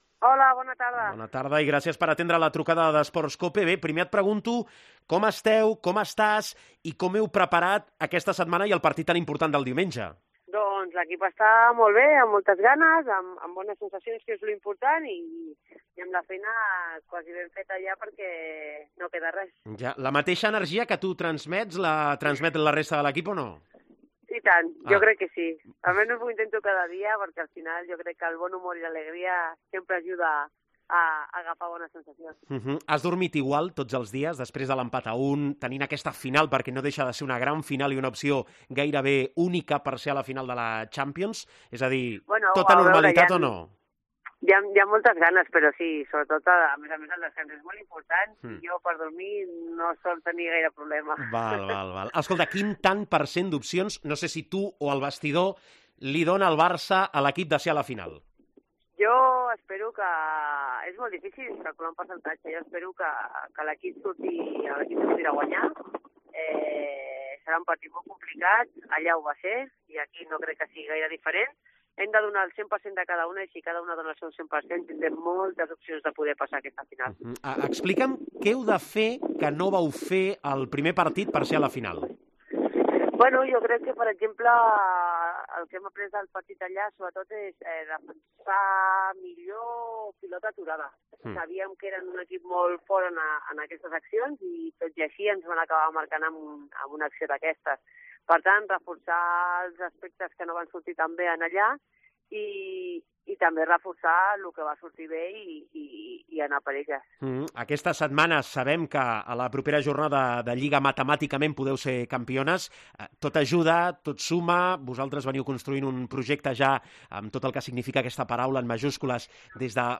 La jugadora del FC Barcelona Marta Torrejón ha pasado por los micrófonos de Esports COPE antes de la gran cita del fin de semana.